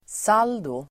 Uttal: [s'al:do]